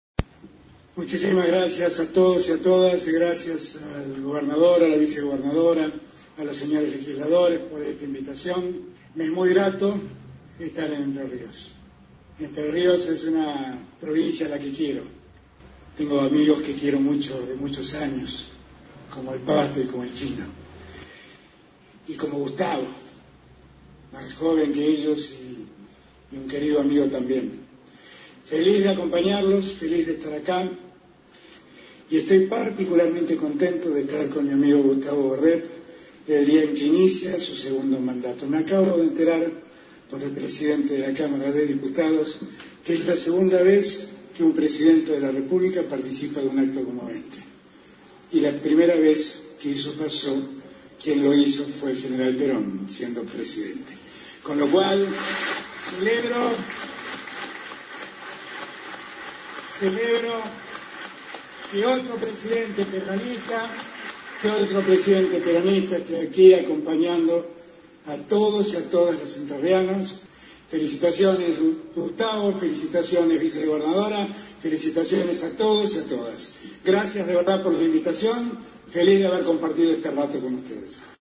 El mandatario brindó dos discursos: uno ante la Asamblea Legislativa, donde le colocó la banda y entregó el bastón de mando a Bordet, y el otro, ante el público que concurrió a la Plaza Mansilla con la esperanza de poder verlo.